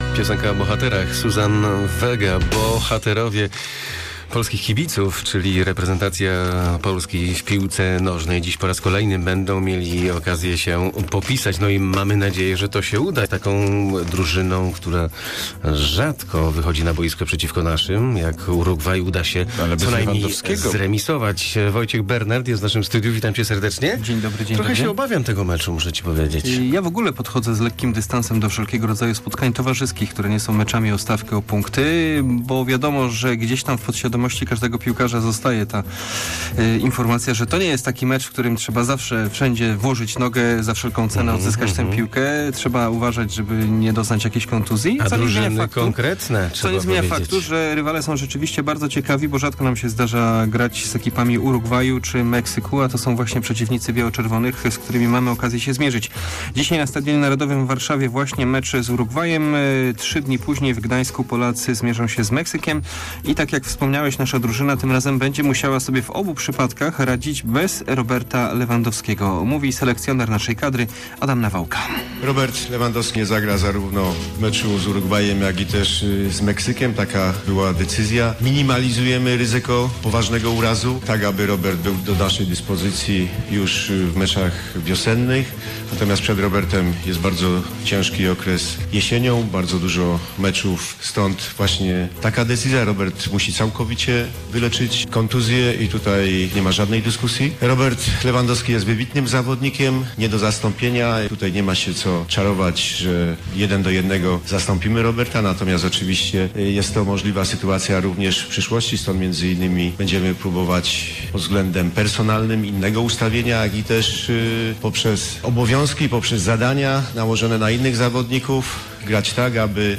10.11 serwis sportowy godz. 7:45
W naszym porannym serwisie selekcjoner piłkarskiej reprezentacji Polski Adam Nawałka tłumaczy dlaczego w towarzyskich meczach z Urugwajem i Meksykiem zabraknie Roberta Lewandowskiego. Podsumowujemy też pierwsze spotkania barażowe o awans do przyszłorocznego Mundialu w Rosji.